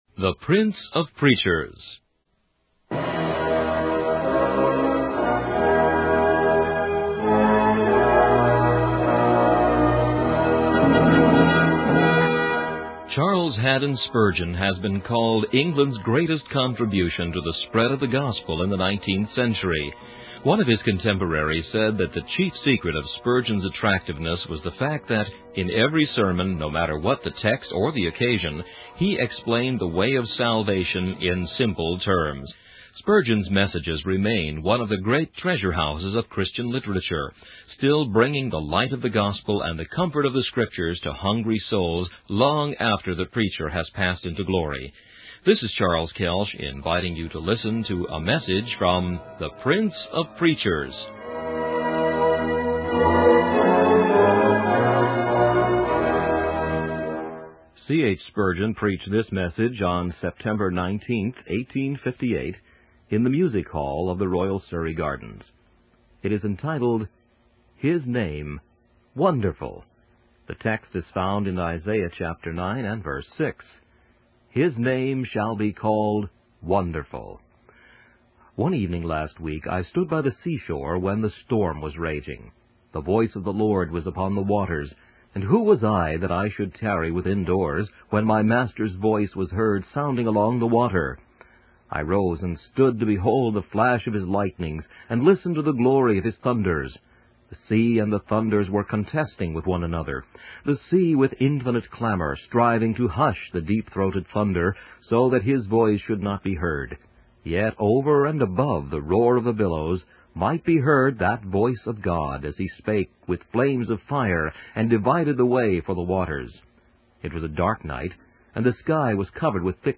In this sermon, the preacher describes a time when the world as we know it will come to an end.